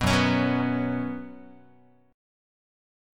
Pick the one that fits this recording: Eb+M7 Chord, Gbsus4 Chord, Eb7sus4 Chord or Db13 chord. Gbsus4 Chord